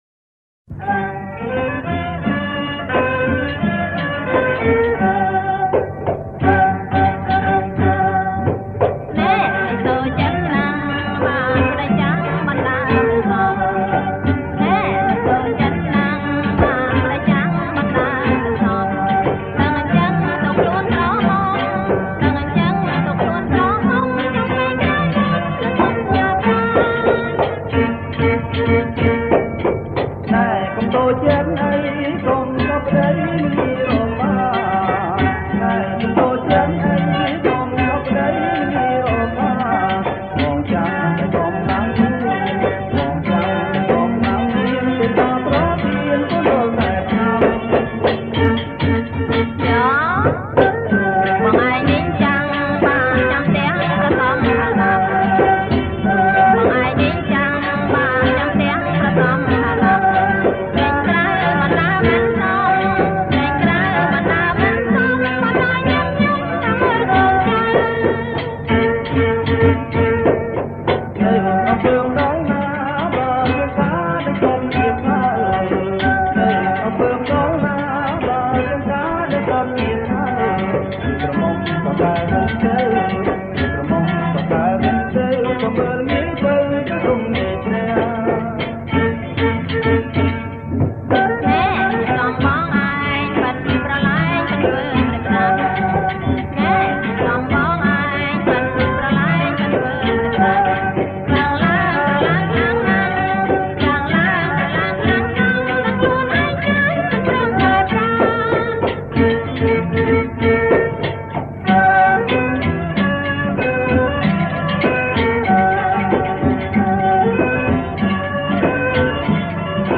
- ក្រប បទទី២ នៅside A